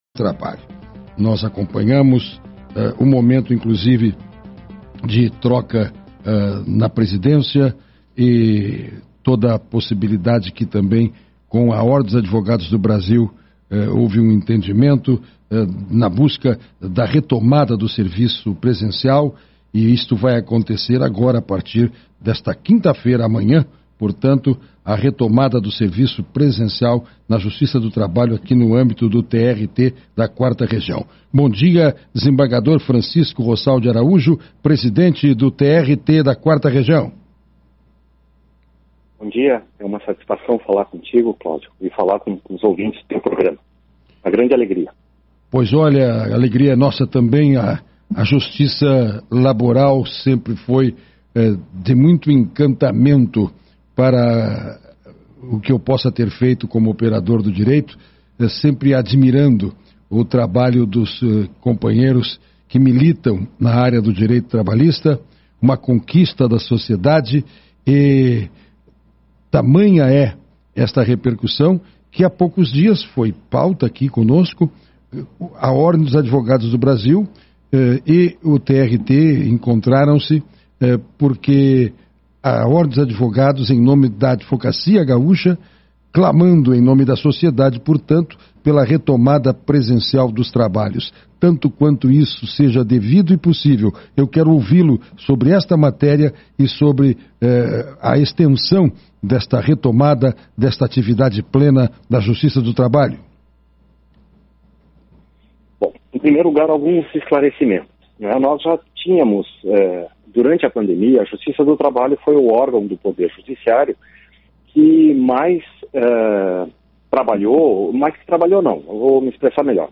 Em entrevista à Rádio ABC, presidente Francisco Rossal destaca atuação de magistrados e servidores durante a pandemia.
Entrevista Des Rossal - Radio ABC.mp3